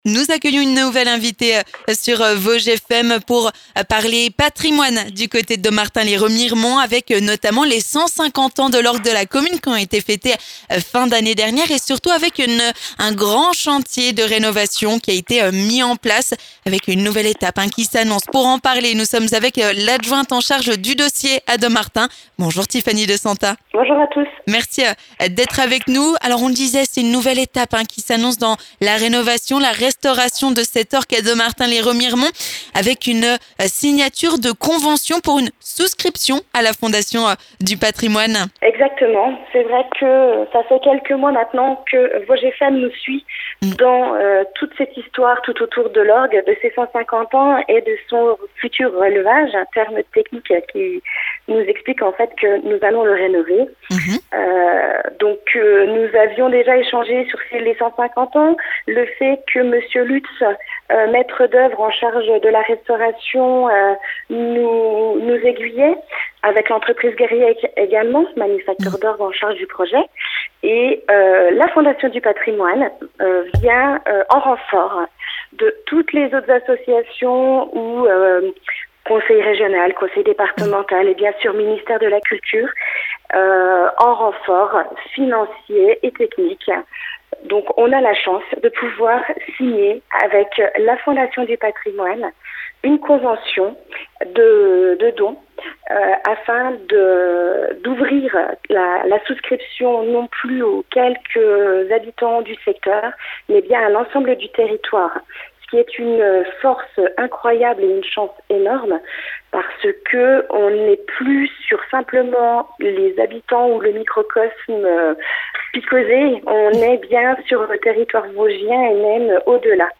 On en parle avec Tiffany De Santa, adjointe à la municipalité de Dommartin-lès-Remiremont en charge de ce dossier.